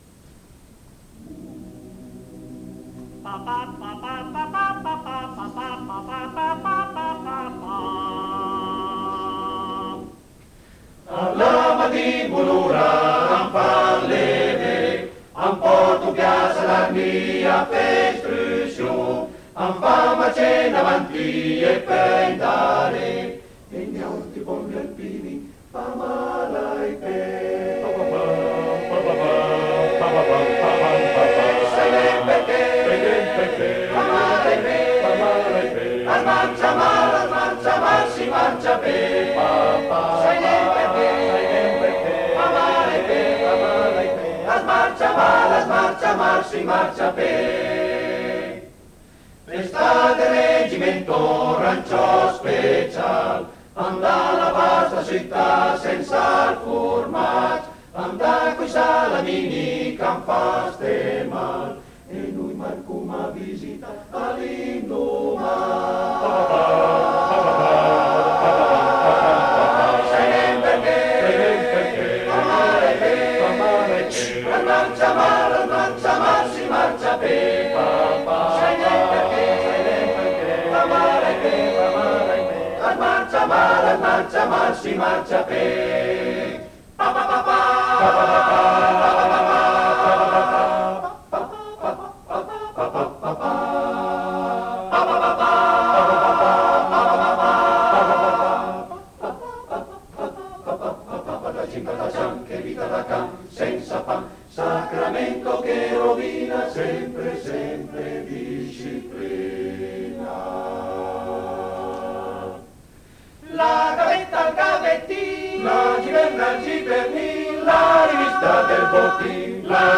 Esecutore: Coro Edelweiss